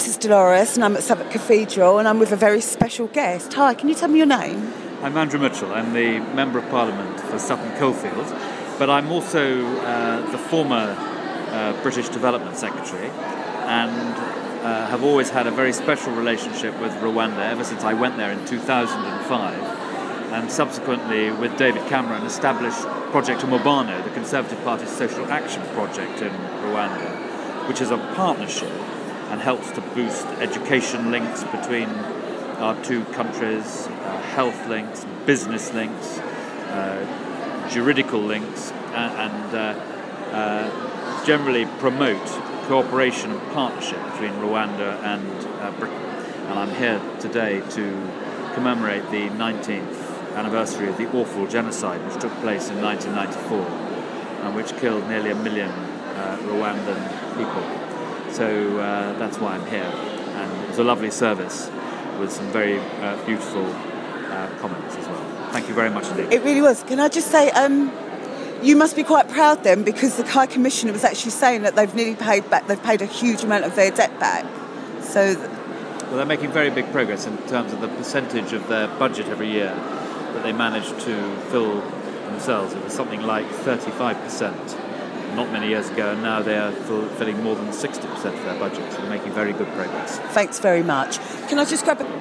Andrew Mitchell MP for Sutton Coldfield tells of Britains links to Rwanda on the anniversary of the genocide